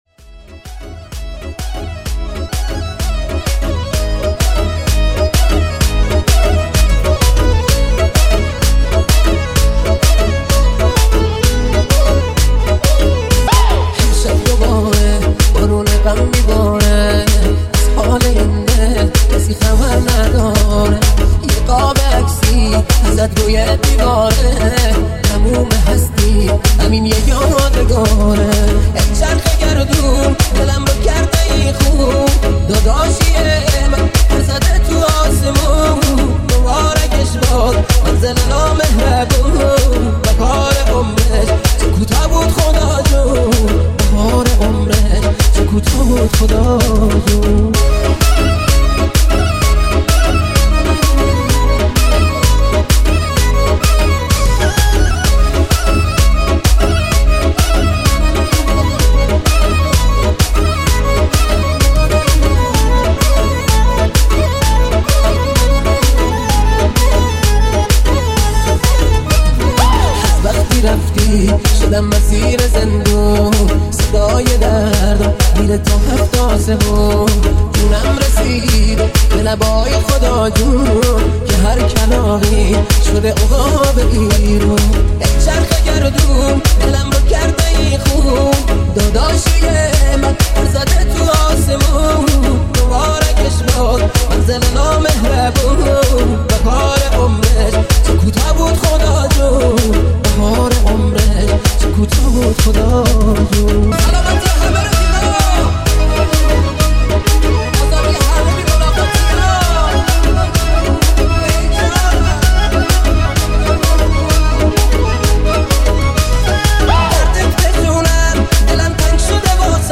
ریمیکس تند و بیسدار